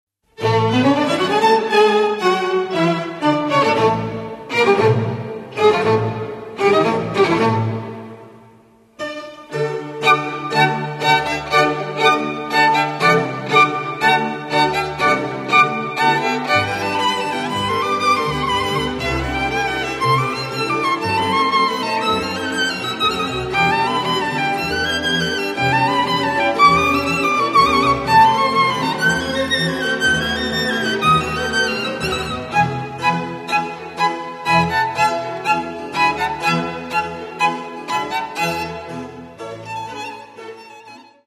Каталог -> Классическая -> Камерная